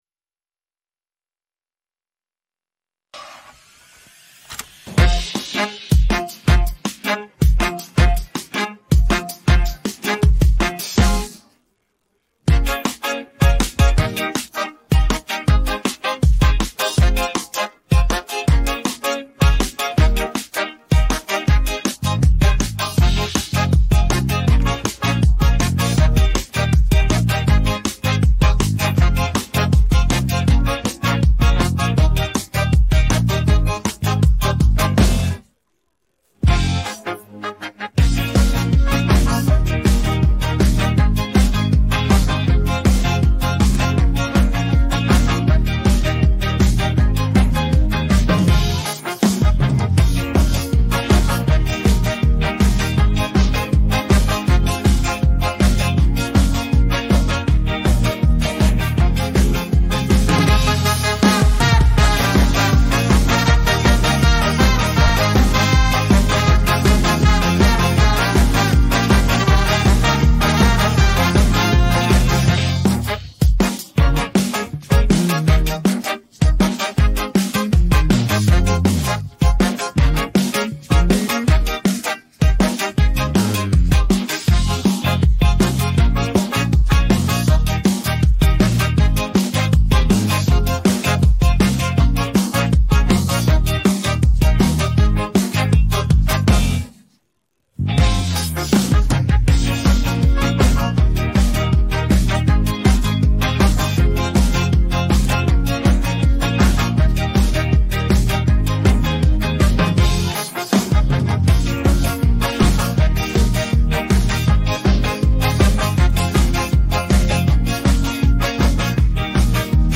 українське караоке 622